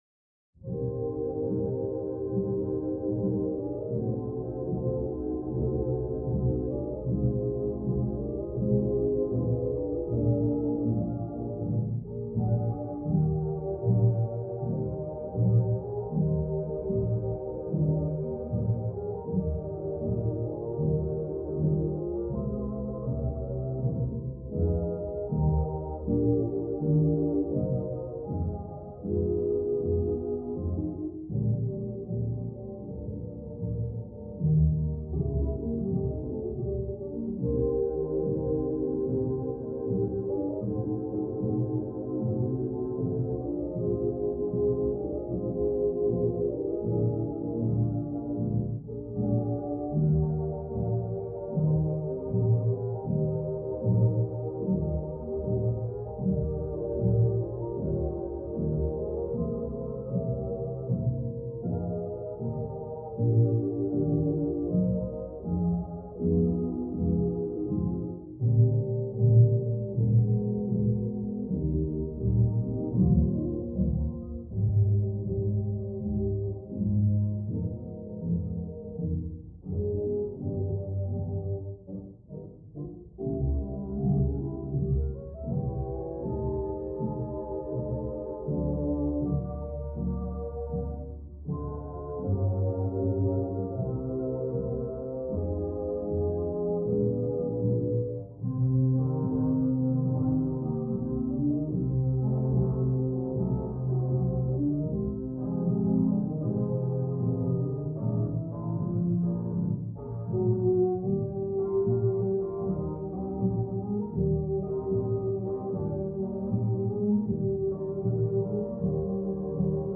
playing from another room